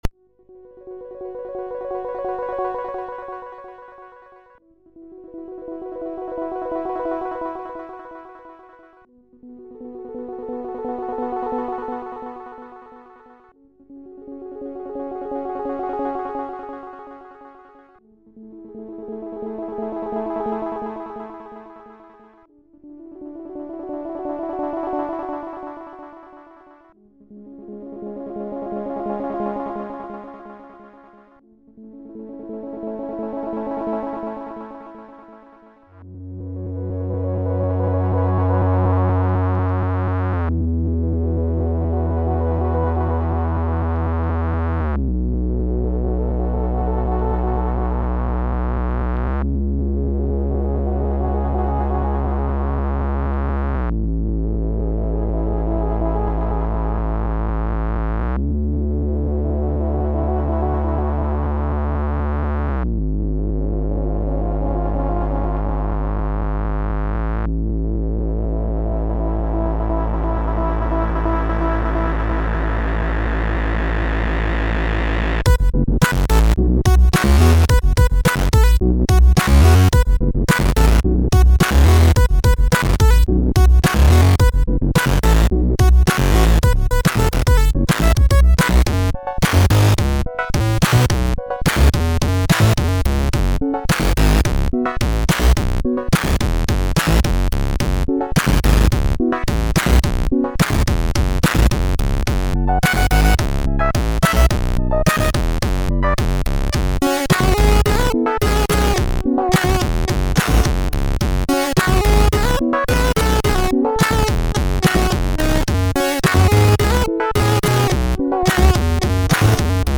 SID Version: 8580 (PAL)
Nice intro at the start, and some real funky tunes.